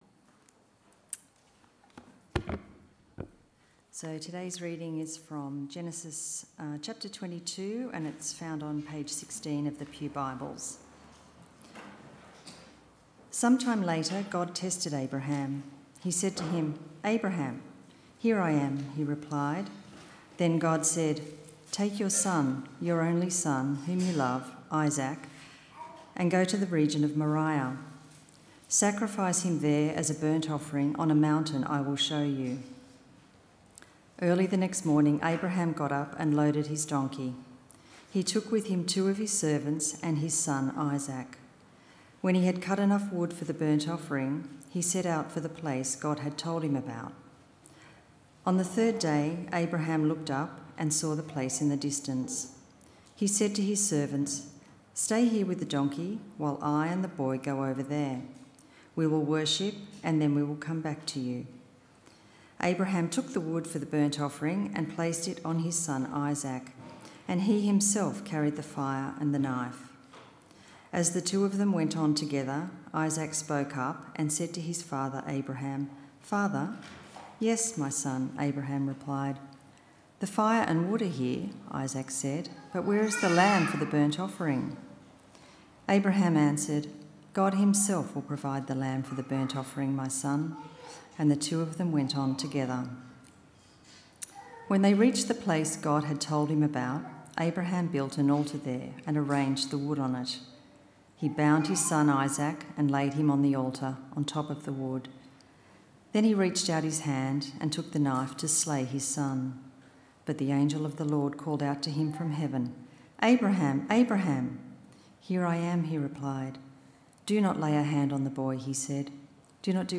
This sermon reflects on Genesis 22, where God tests Abraham by asking him to sacrifice his son, Isaac.